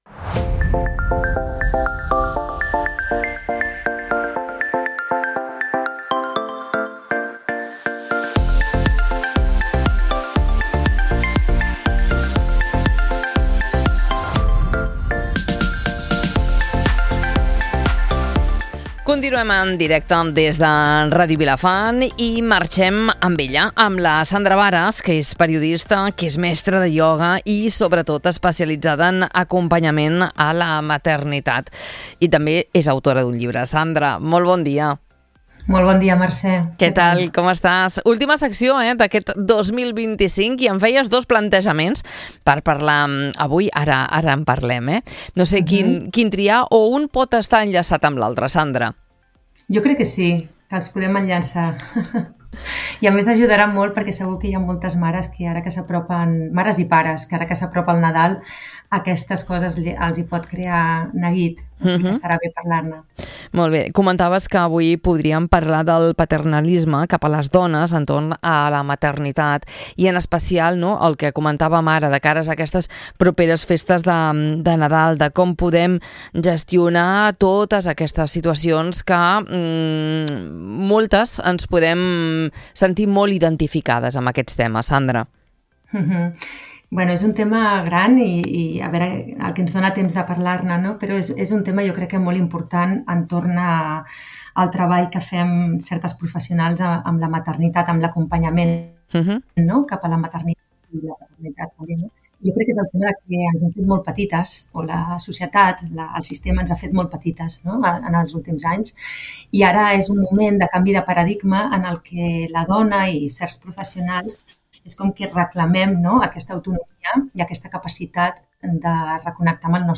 Una conversa necessària per validar experiències, obrir consciència i recordar que cada maternitat és única . https